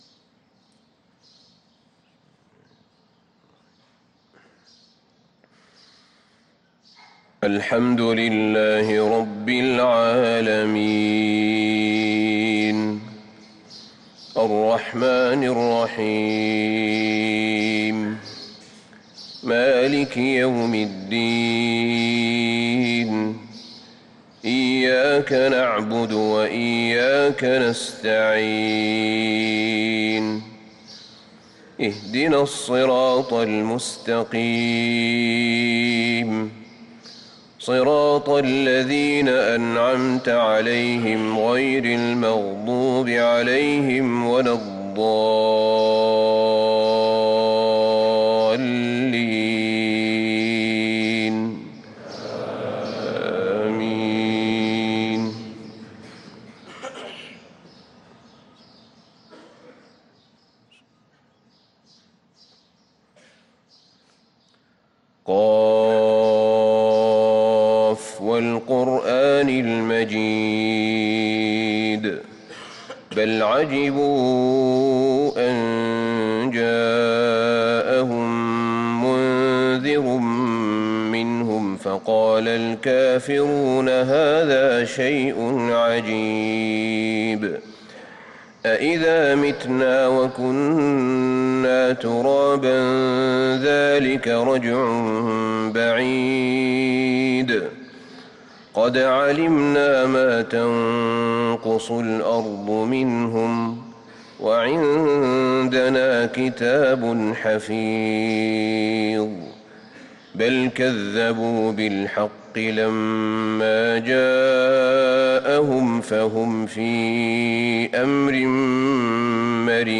صلاة الفجر للقارئ أحمد بن طالب حميد 29 جمادي الآخر 1445 هـ
تِلَاوَات الْحَرَمَيْن .